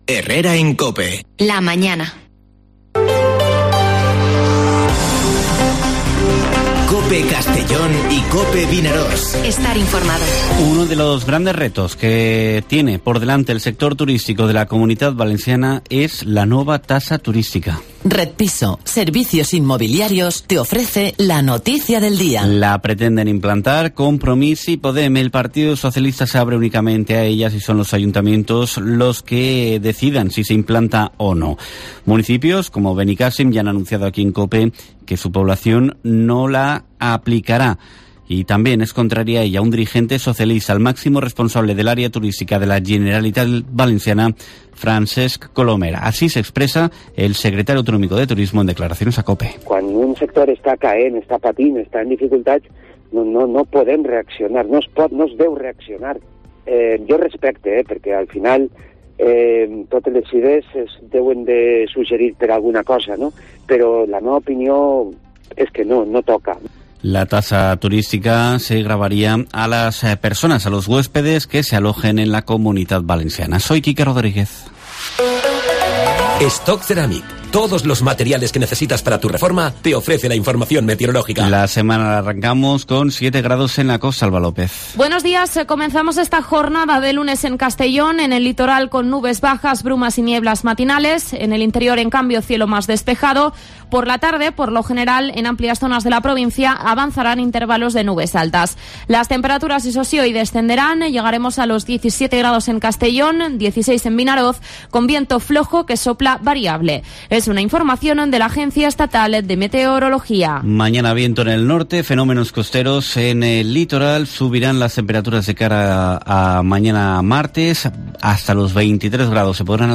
Informativo Herrera en COPE en la provincia de Castellón (03/01/2022)